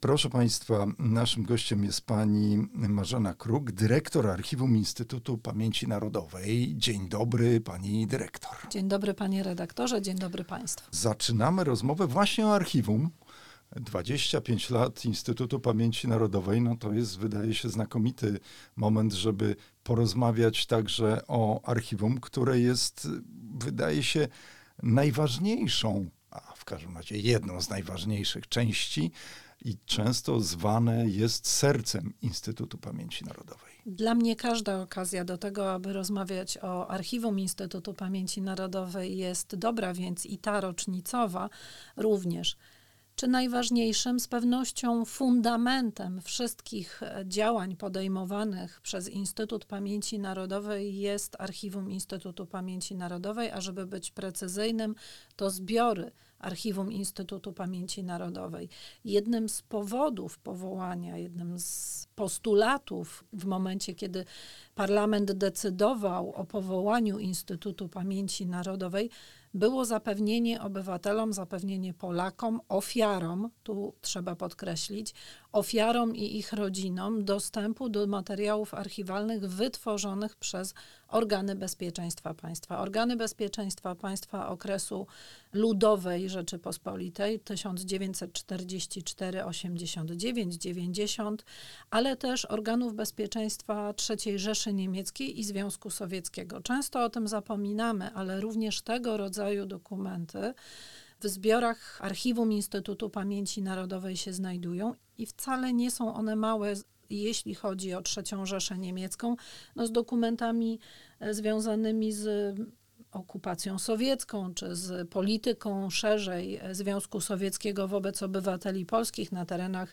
Archiwum pamięci – historia dostępna. Rozmowa